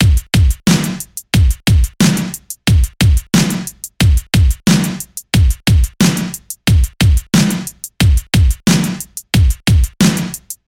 80-s-old-school-rap-drum-loop